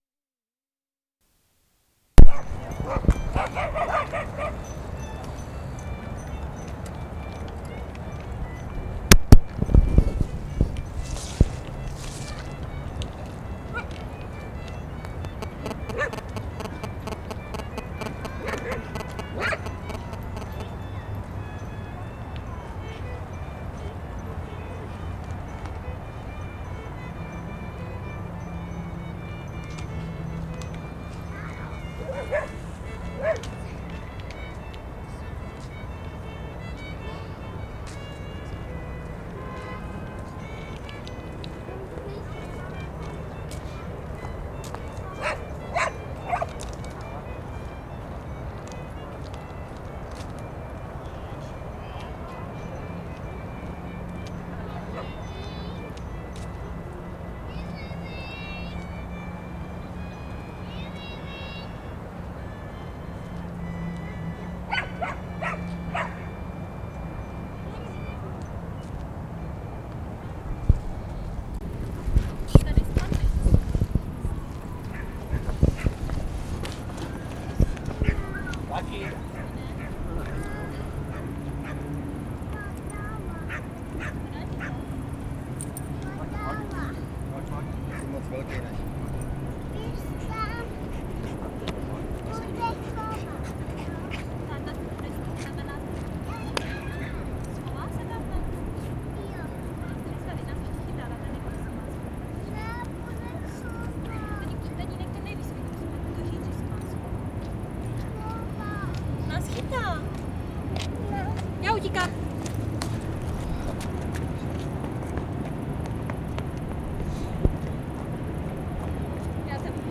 Recording made in: Parukářka , Prague, October 2004.
field recordings internationalisms listening music processes what the found sound